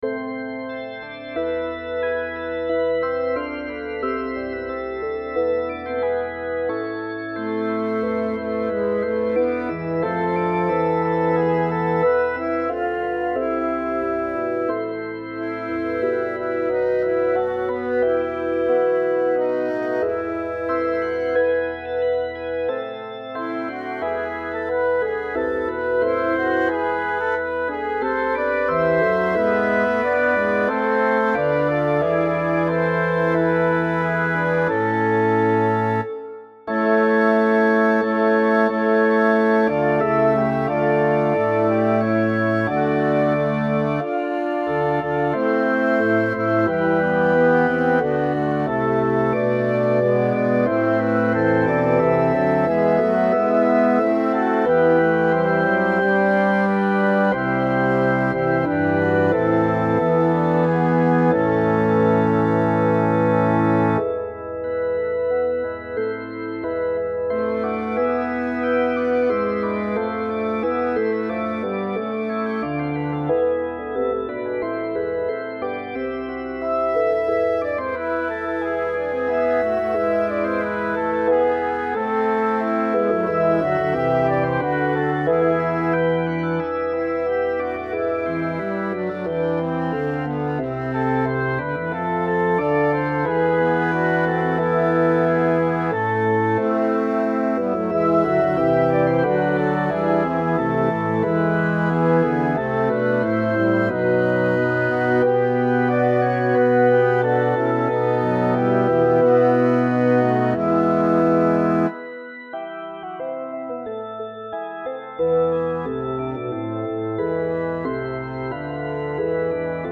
Title: Give the King thy judgements Composer: Henry Loosemore Lyricist: Number of voices: 7vv Voicings: SSAATBB or AATTBBB Genre: Sacred, Anthem
Language: English Instruments: Organ
First published: ca. 1640 Description: This is one of Loosemore’s more elaborate verse anthems.